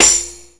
tambourin_shot01.mp3